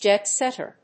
アクセントjét sètter